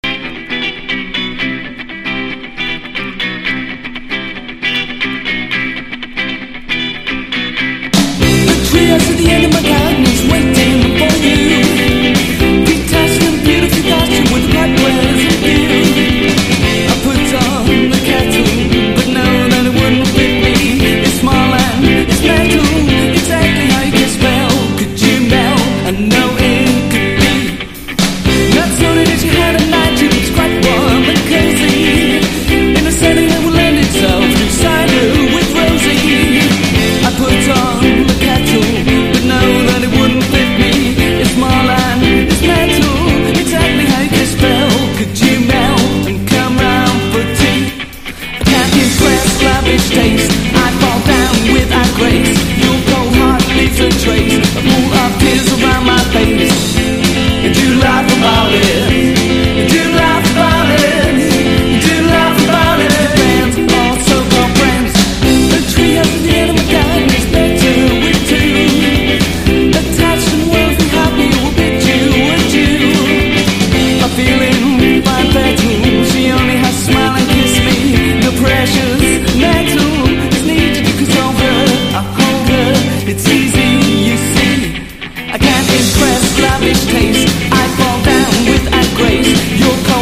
NEO ACOUSTIC / GUITAR POP